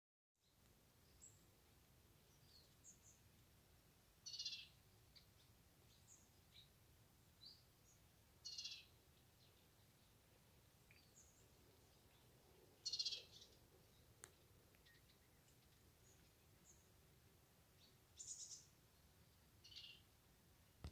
Icterine Warbler, Hippolais icterina
Administratīvā teritorijaIecavas novads
StatusAgitated behaviour or anxiety calls from adults